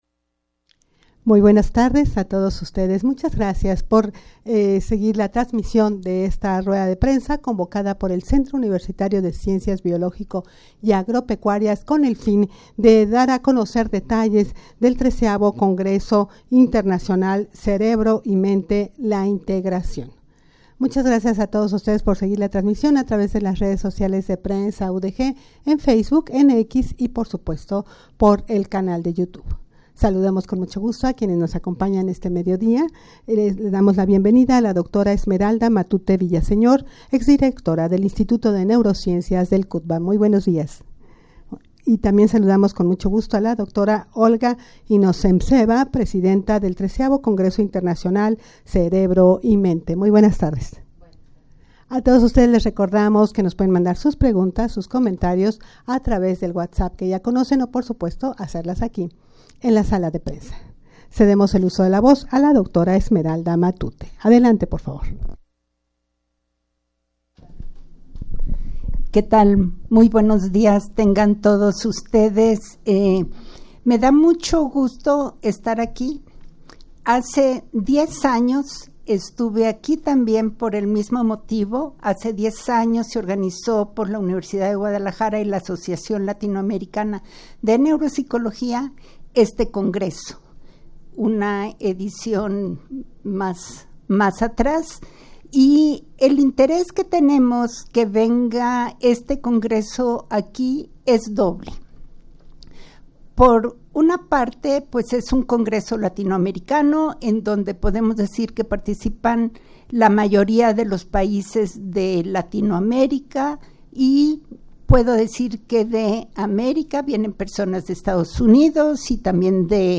Audio de la Rueda de Prensa
rueda-de-prensa-para-dar-a-conocer-las-inscripciones-y-actividades-al-xiii-congreso-internacional-cerebro-y-mente.mp3